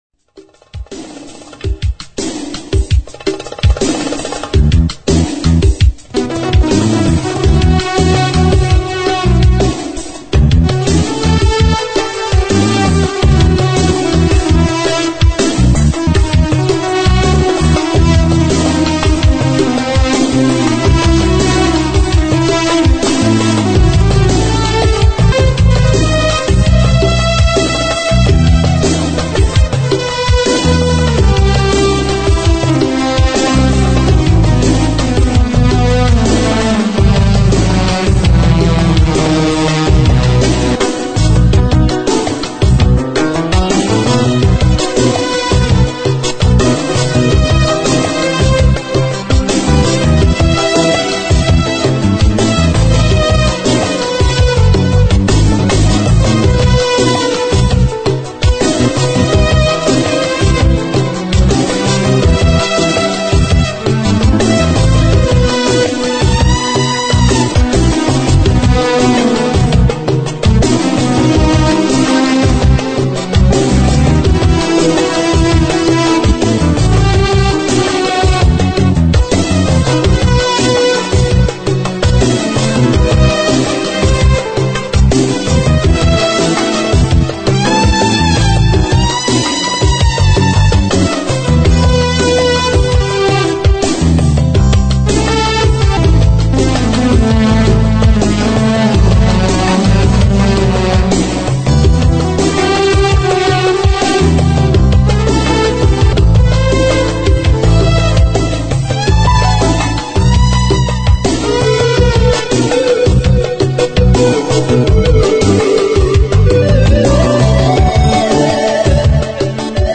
musique instrumentale